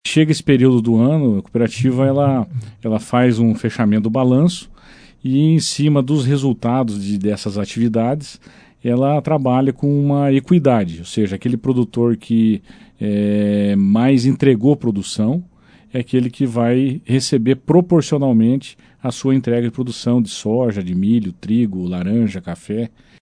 ENTREVISTA-COCAMAR-01-1.mp3